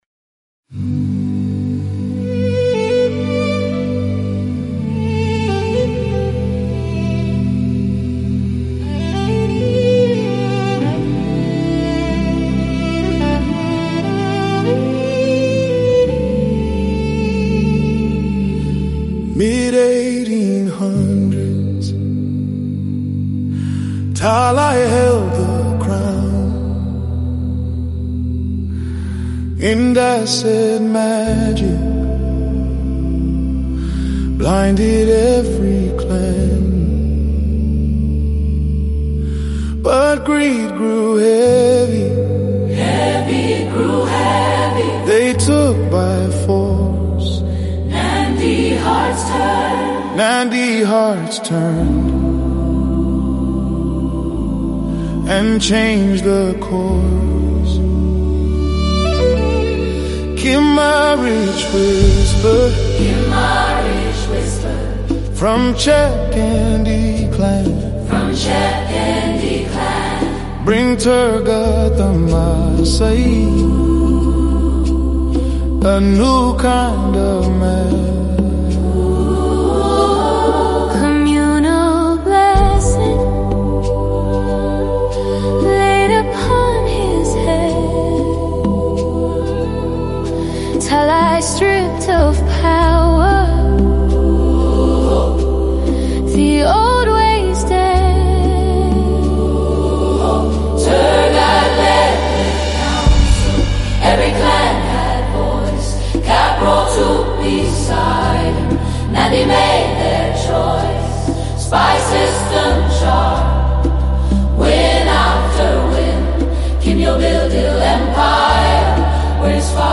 AI-composed music honoring Koitaleel Samoei and the larger House of Turgat
These songs breathe life into ancient Kalenjin rhythms, prophetic chants, and warrior spirit — composed with AI as a collaborator, honoring those who held the line.
The House of Turgat · ancestral chant & drums